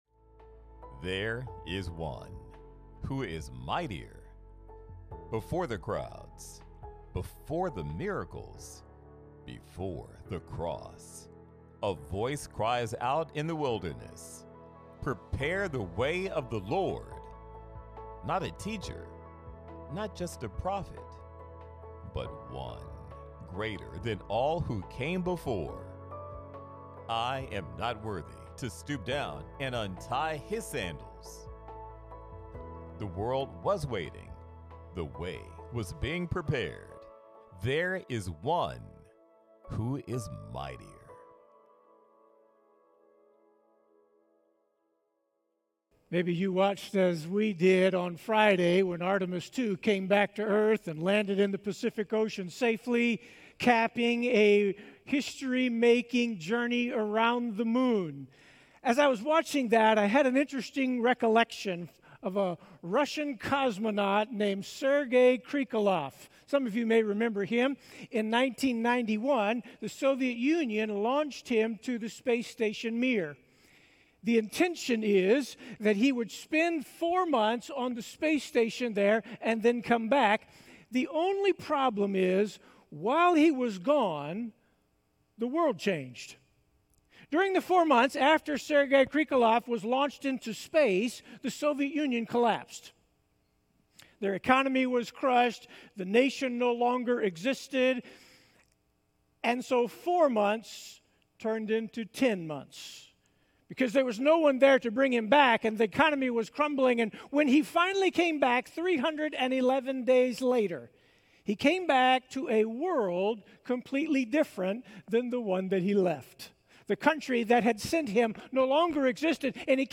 Sermon Listen Mark's Gospel introduces Jesus as the one who changed everything in human history.